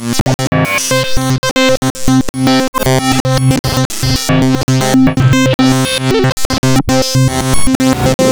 Here are 5 (64 slice) glitchy chains from randomly selected samples of my Polyend Medusa in 48k/16 suitable for dropping straight into the Digitakt :slight_smile: